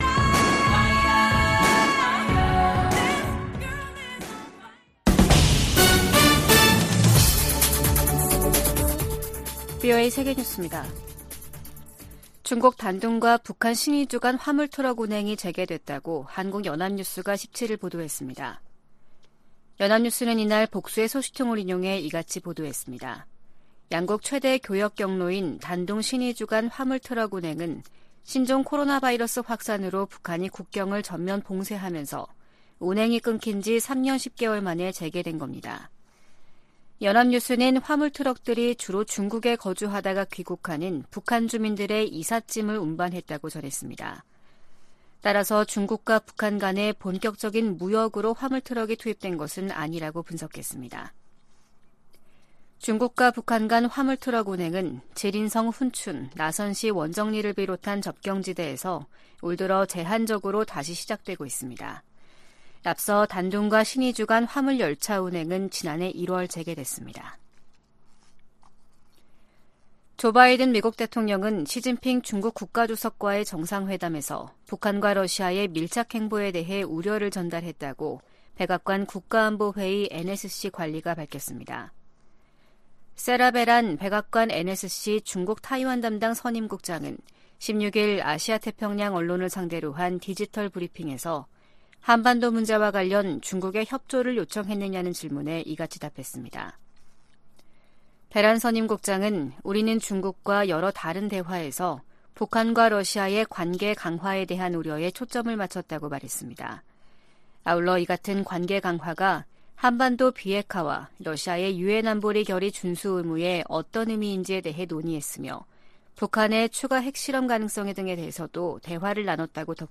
VOA 한국어 아침 뉴스 프로그램 '워싱턴 뉴스 광장' 2023년 11월 18일 방송입니다. 조 바이든 미국 대통령과 기시다 후미오 일본 총리가 타이완해협, 한반도, 동중국해 등에서의 평화와 안정이 중요하다는 점을 재확인했습니다. 미국은 동맹국의 핵무기 추구를 단호히 반대해야 한다고 국무부의 안보 관련 자문위원회가 주장했습니다. 미중 정상이 양국 간 갈등을 완화하는 데 동의함으로써 한반도 안보 상황에도 긍정적으로 작용할 것이라는 관측이 나오고 있습니다.